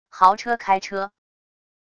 豪车开车wav音频